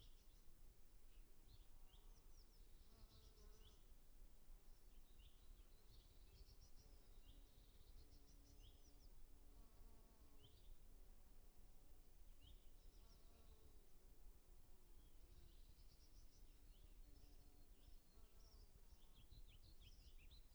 rainforest-birds.wav